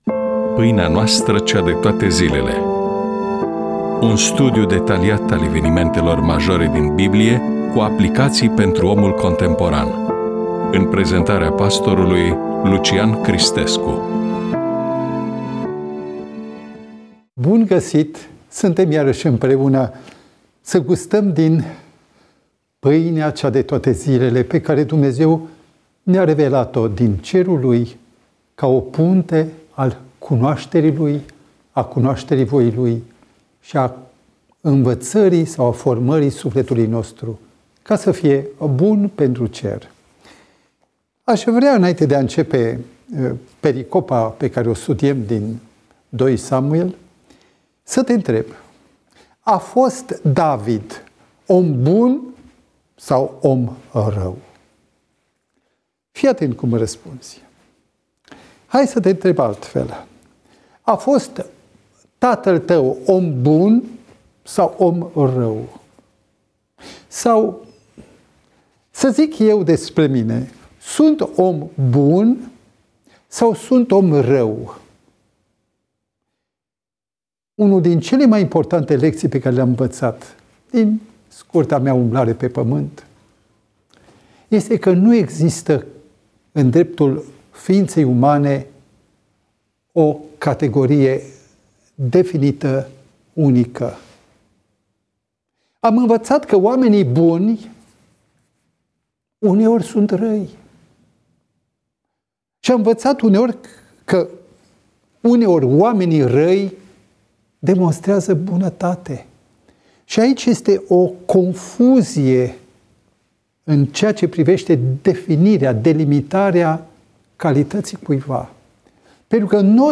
EMISIUNEA: Predică DATA INREGISTRARII: 13.03.2026 VIZUALIZARI: 29